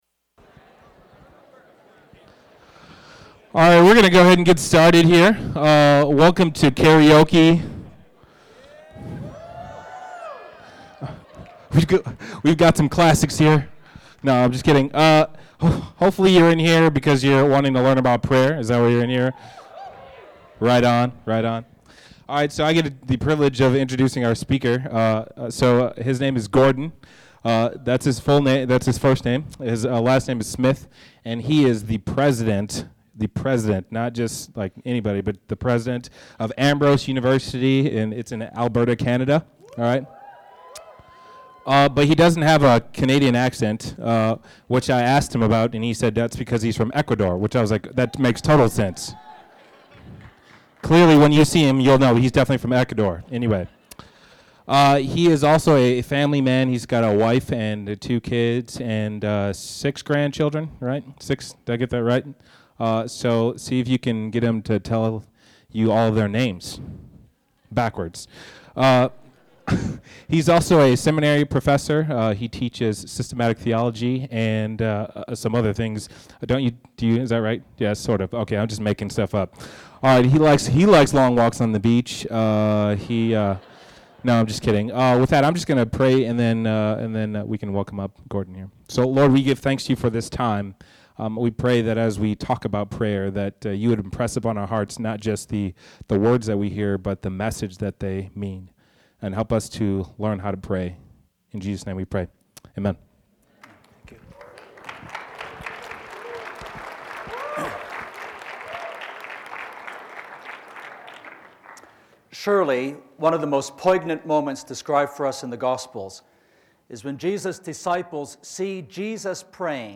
This seminar will consider how the prayer “thy kingdom come” can inform and infuse our daily lives with a vision for the kingdom and participation in the kingdom work of God.